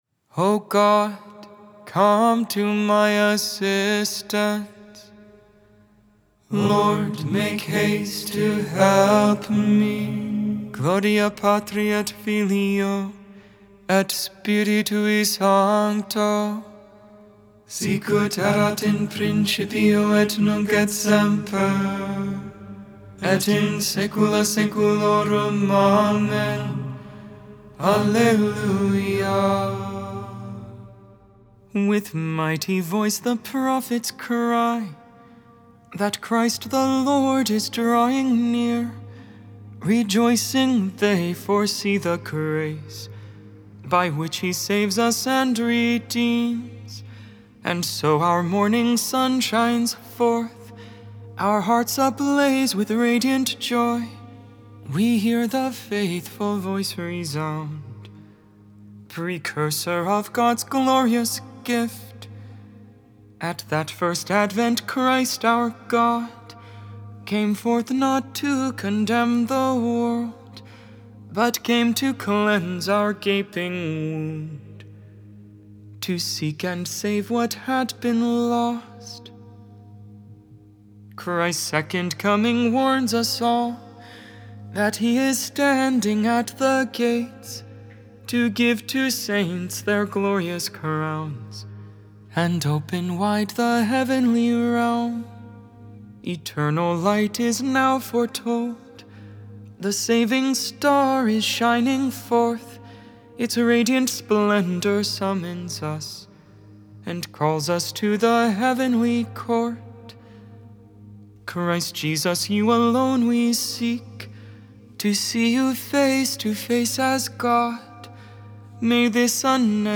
Benedictus (English, Gregorian tone 8)